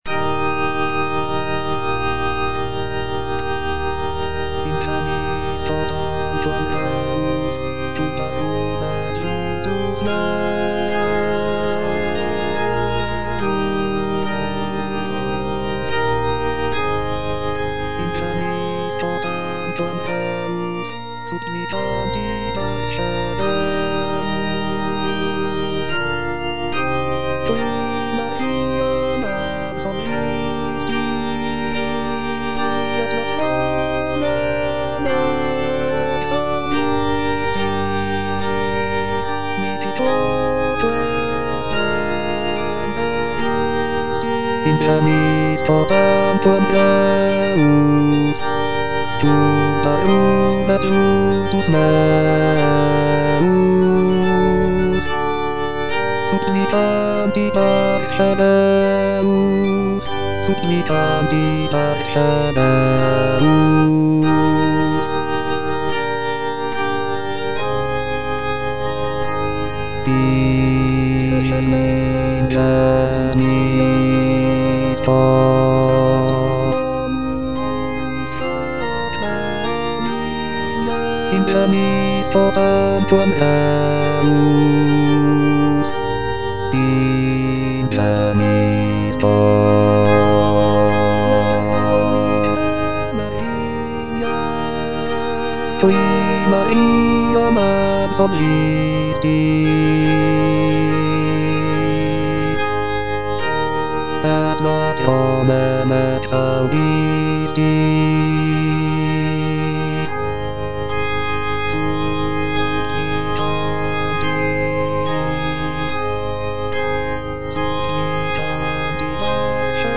(bass II) (Voice with metronome) Ads stop
is a sacred choral work rooted in his Christian faith.